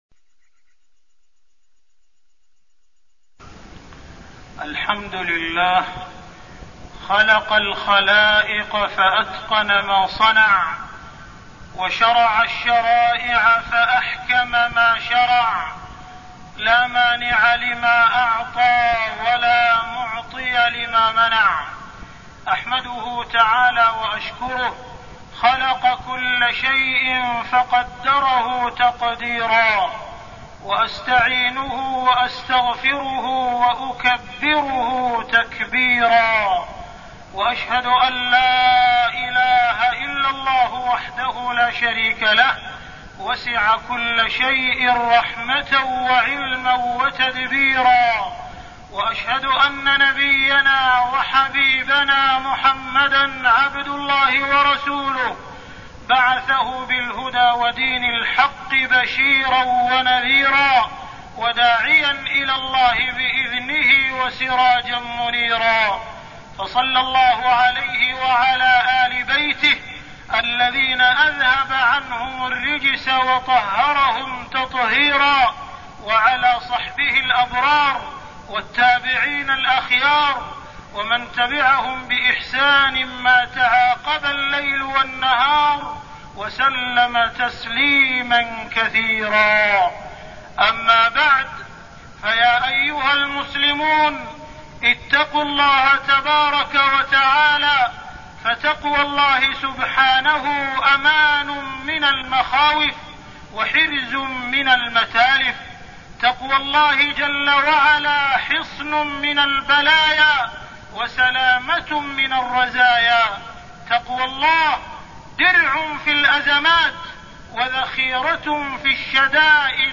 تاريخ النشر ٢٤ جمادى الآخرة ١٤١٦ هـ المكان: المسجد الحرام الشيخ: معالي الشيخ أ.د. عبدالرحمن بن عبدالعزيز السديس معالي الشيخ أ.د. عبدالرحمن بن عبدالعزيز السديس حرمة دم المسلم